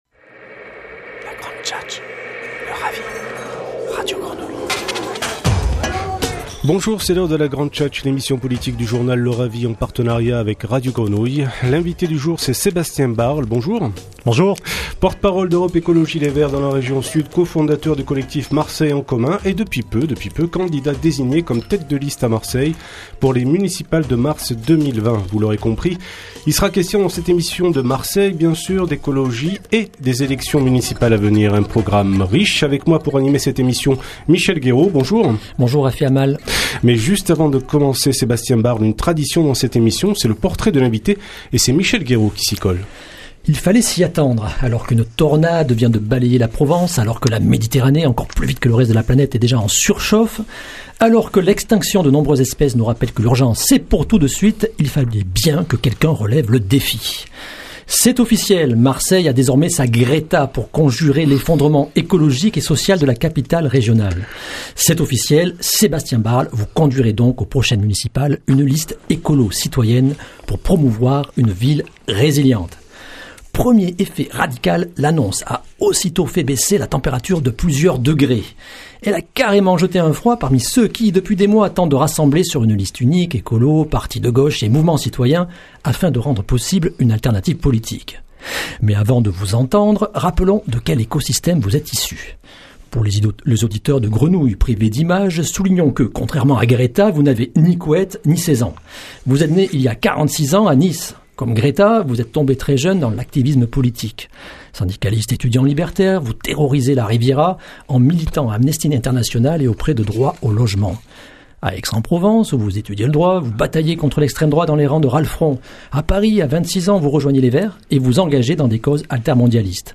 Entretien en partenariat avec Radio Grenouille